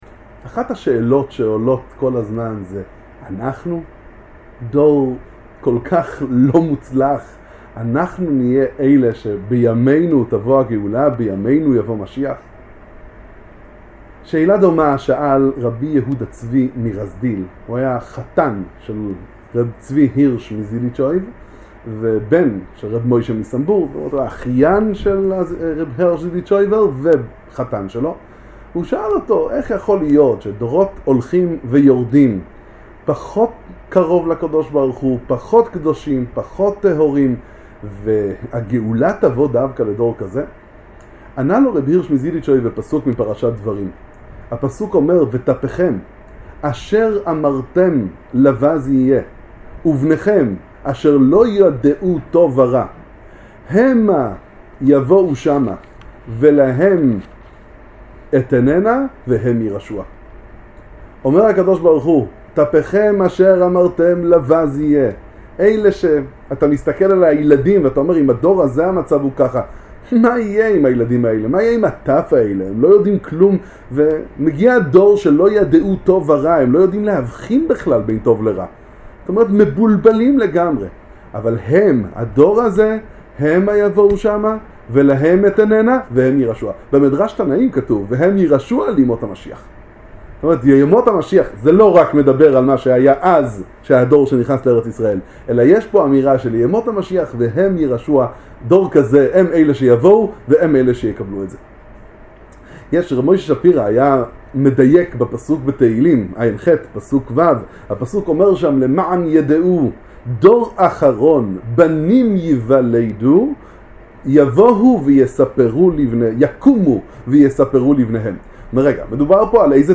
הדור האחרון, איך יתכן שדווקא לדור שפל שכזה תבוא הגאולה? דבר תורה קצר לפרשת דברים ולתשעת הימים